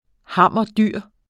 Udtale [ ˈhɑmˀʌˈ- ]